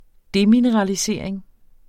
demineralisering substantiv, fælleskøn Bøjning -en Udtale [ ˈdeminəʁɑliˌseɐ̯ˀeŋ ] Betydninger 1.